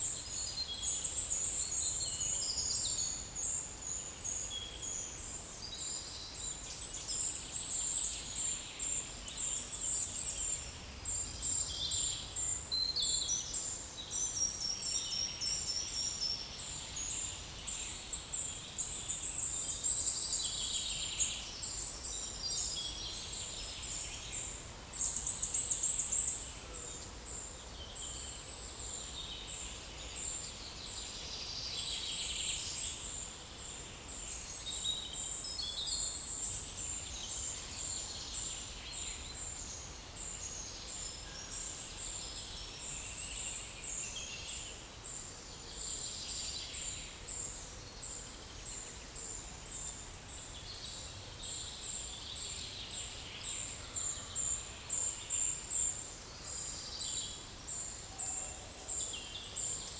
Monitor PAM
Certhia familiaris
Certhia brachydactyla
Regulus ignicapilla
Sitta europaea